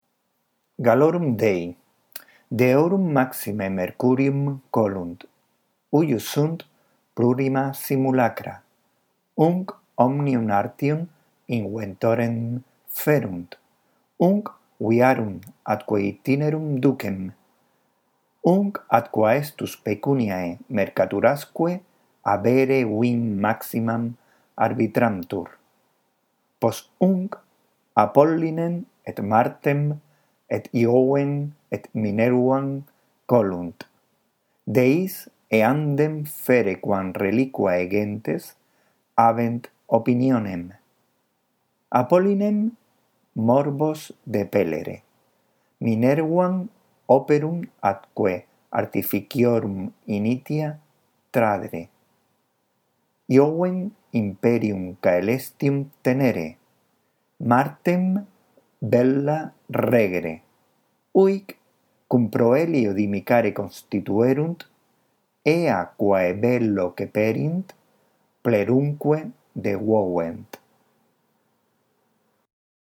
La audición de este archivo te ayudará en la práctica de la lectura del latín: